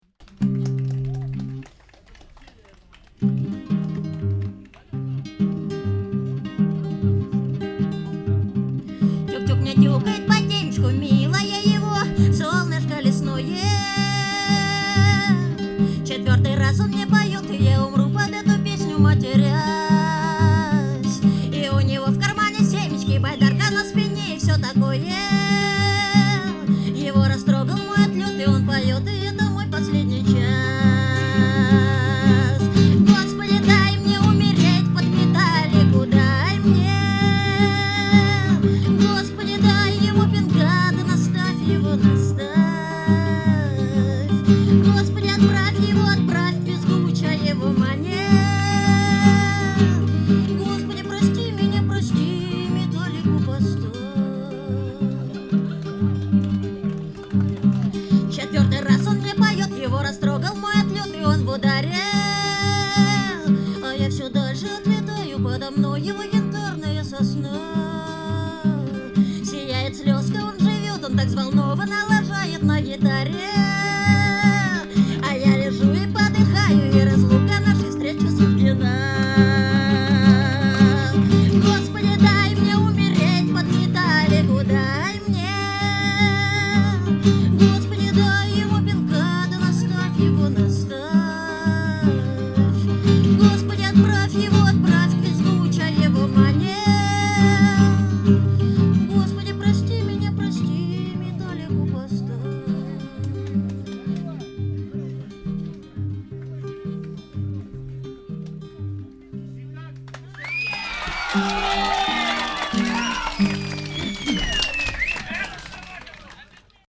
Запись концерта БС2002